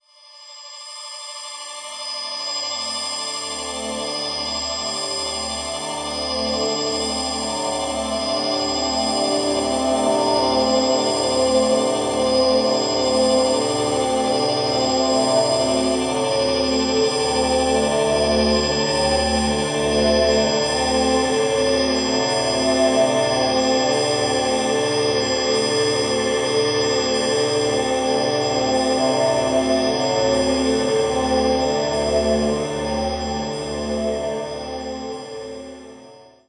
12 - Shimmer Drone
12_ShimmerDrone.wav